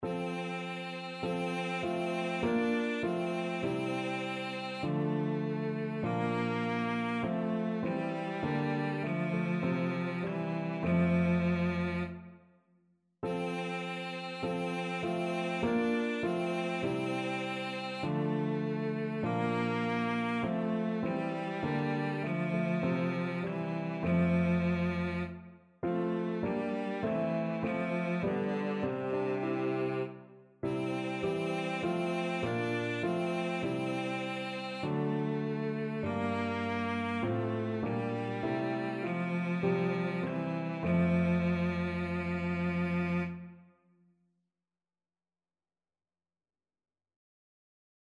Cello
F major (Sounding Pitch) (View more F major Music for Cello )
4/4 (View more 4/4 Music)
Classical (View more Classical Cello Music)